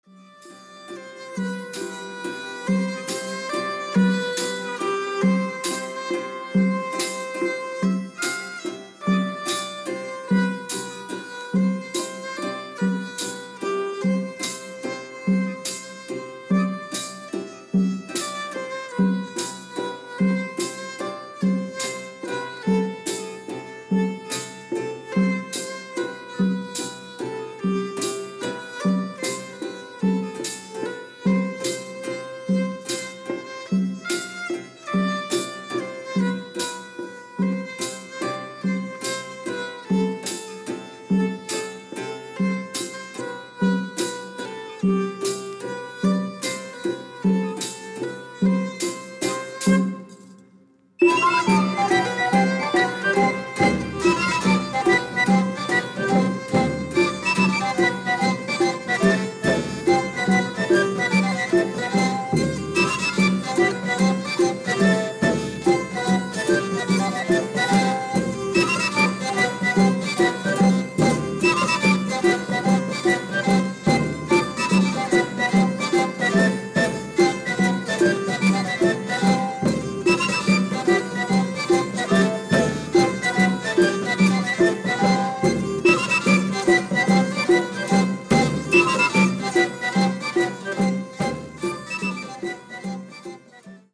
のんびり古楽に浸るのもステキです。